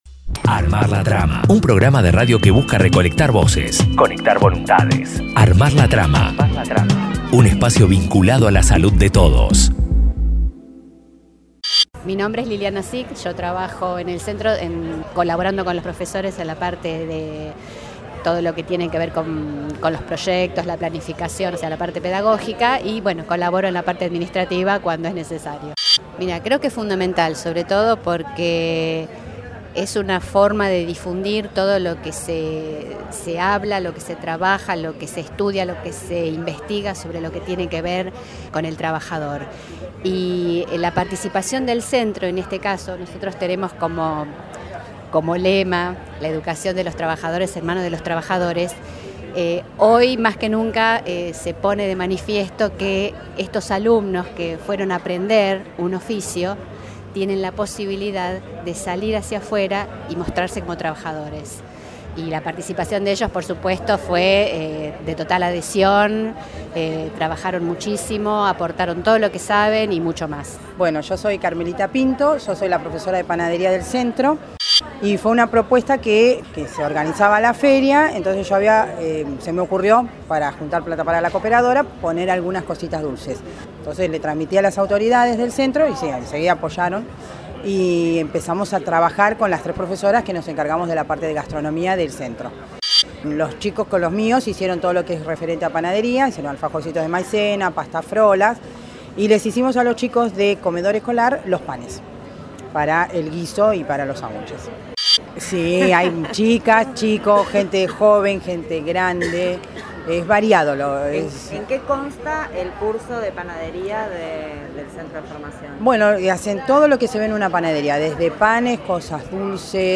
Armar la Trama conversó con las docentes que dirigen los cursos de Panadería, Repostería y Cocina para Comedor, que llevaron adelante la producción y venta de variadas delicias